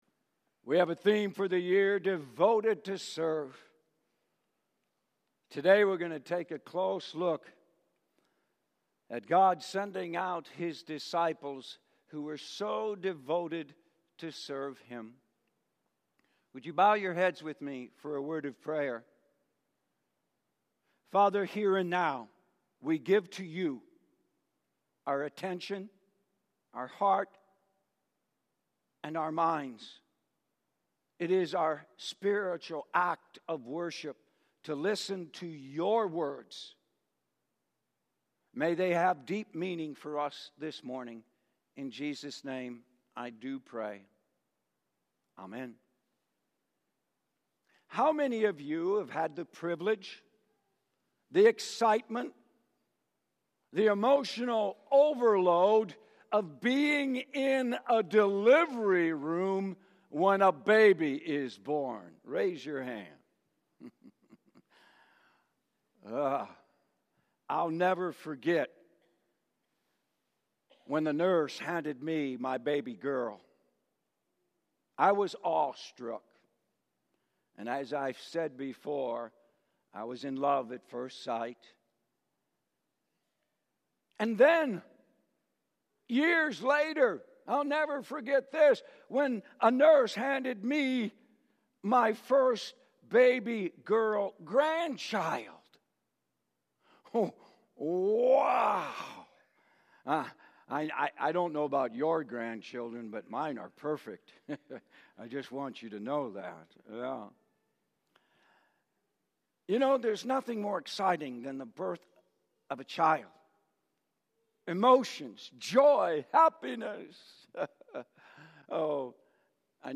Messages – OSLTULSA